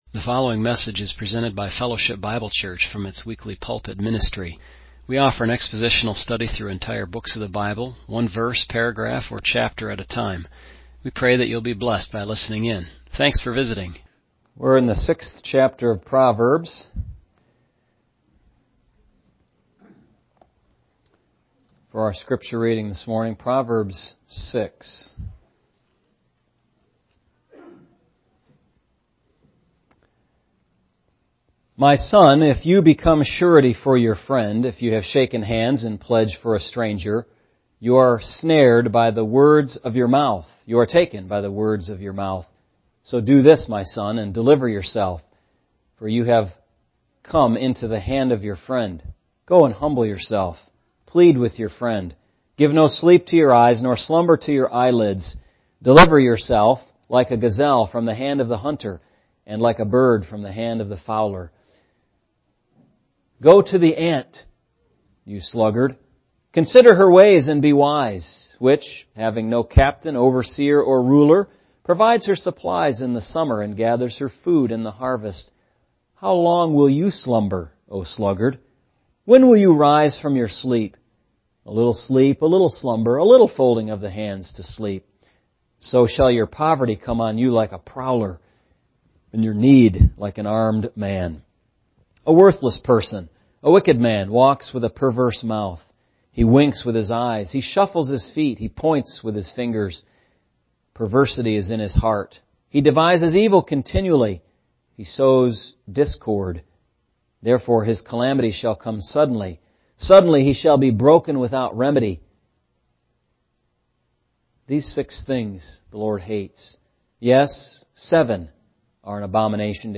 MP3 recordings of sermons and Bible studies for the Sunday ministries at the church.